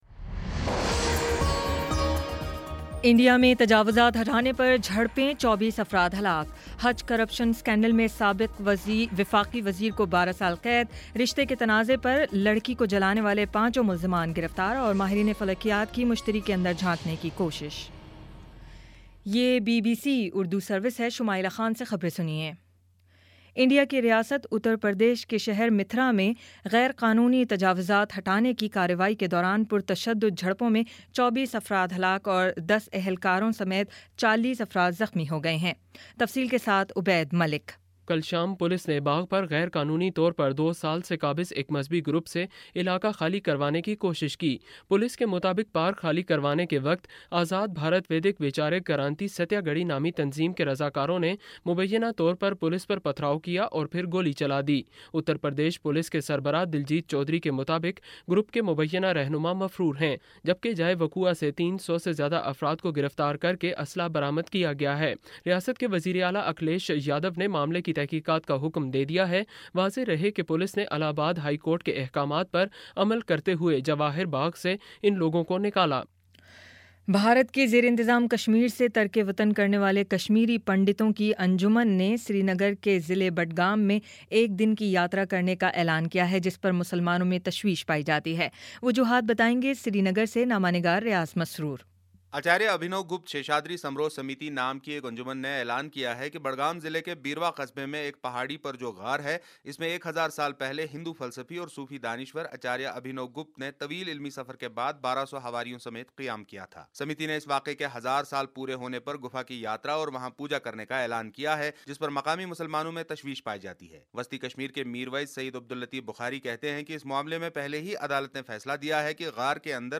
جون 03: شام پانچ بجے کا نیوز بُلیٹن